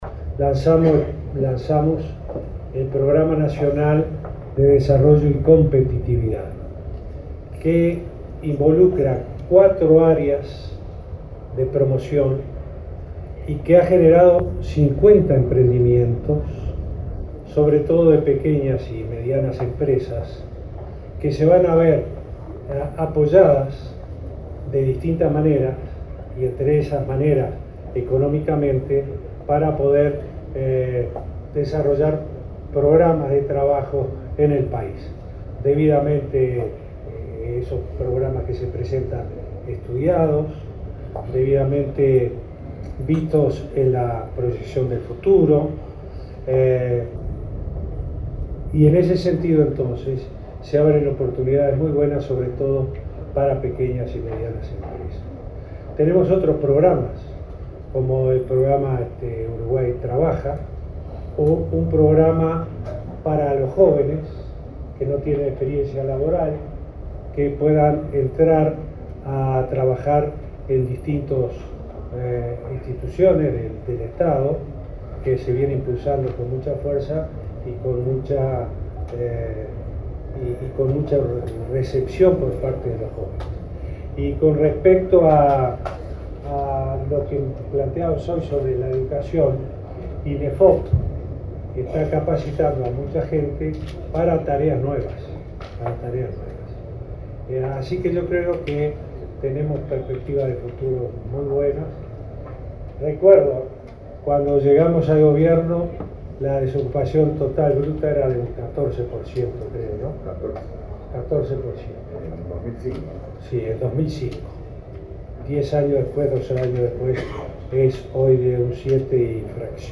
El presidente Tabaré Vázquez, ante residentes uruguayos en México, destacó que una de las herramientas diseñadas por el Gobierno en el tema laboral es el Programa Nacional de Desarrollo y Competitividad, y que involucra cuatro áreas de promoción y ha generado 50 emprendimientos que se verán apoyadas para desarrollar programas de trabajo. Recordó que se implementan además programas de capacitación e inserción.